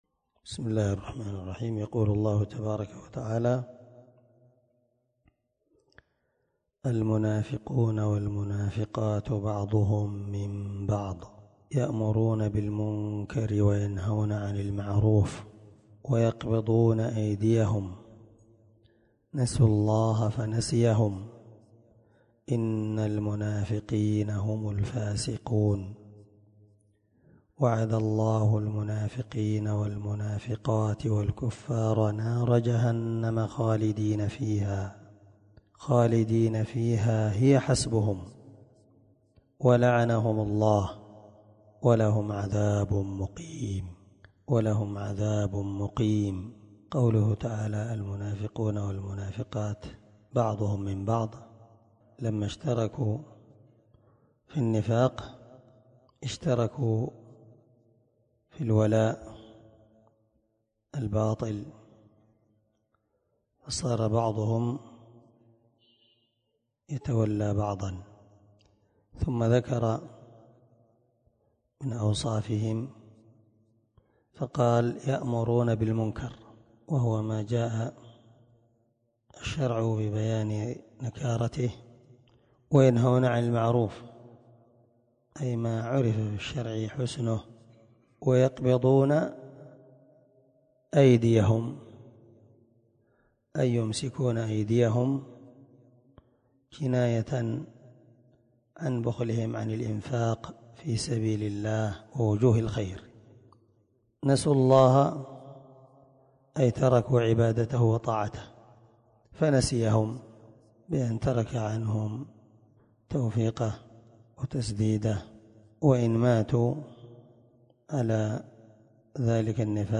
559الدرس30تفسير آية ( 67_68) من سورة التوبة من تفسير القران الكريم مع قراءة لتفسير السعدي